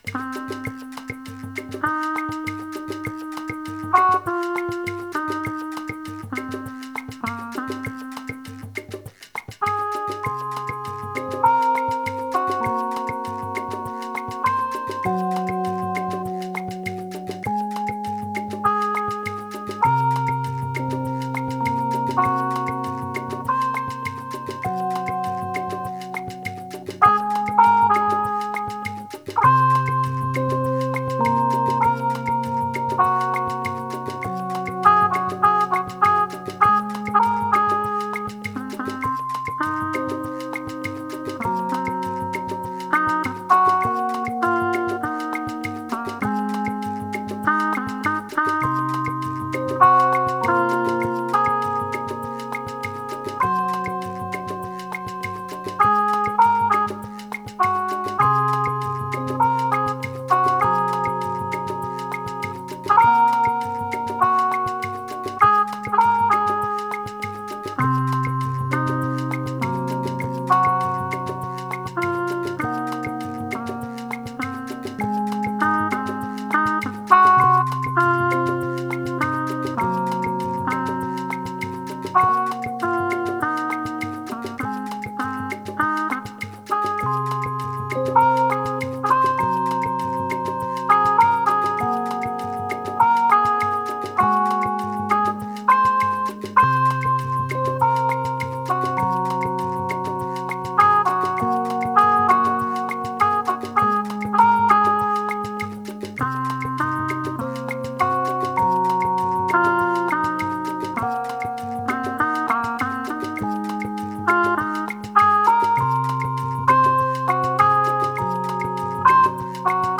Tempo: 100bpm / Datum:12.01.2018